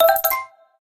open_gift.mp3